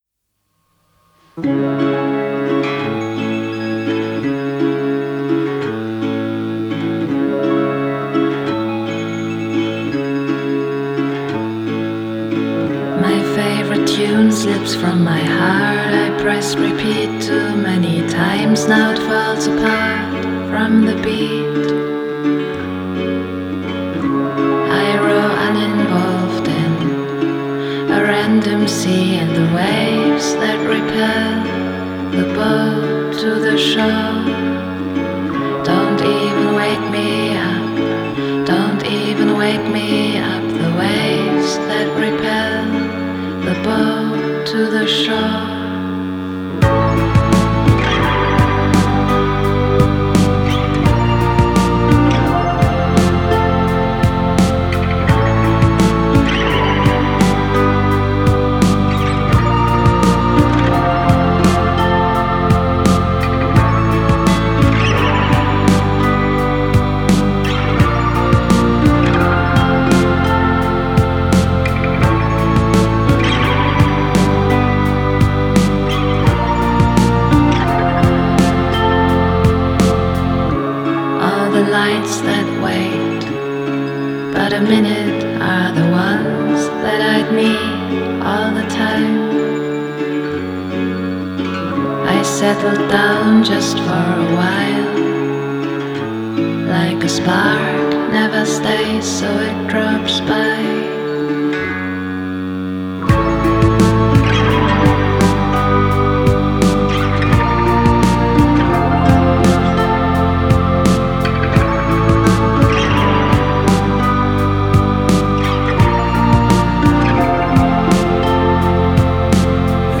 Genre: Dream Pop, Indie Folk, Singer-Songwriter